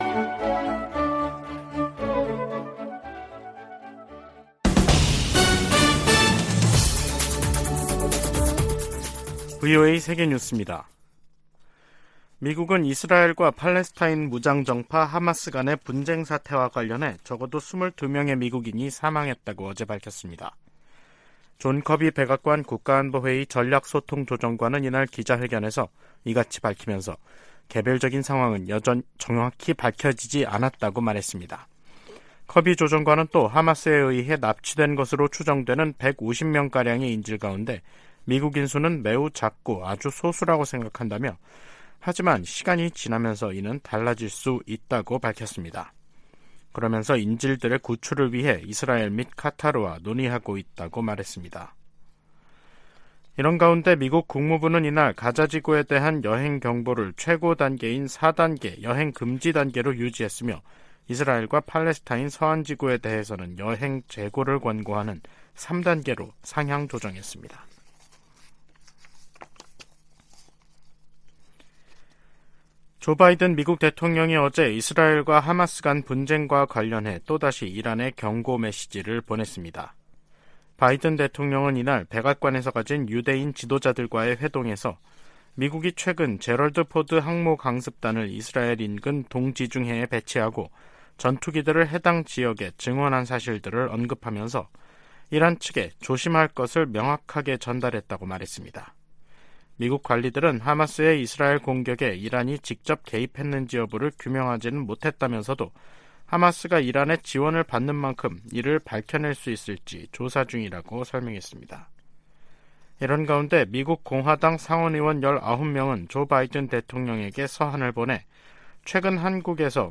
세계 뉴스와 함께 미국의 모든 것을 소개하는 '생방송 여기는 워싱턴입니다', 2023년 10월 12일 저녁 방송입니다. '지구촌 오늘'에서는 팔레스타인 무장 정파 하마스가 인질들을 석방해야 가자지구 봉쇄를 풀 수 있다고 이스라엘 정부가 밝힌 소식 전해드리고, '아메리카 나우'에서는 공화당이 하원의장 후보로 스티브 스컬리스 하원 공화당 대표를 선출한 이야기 살펴보겠습니다.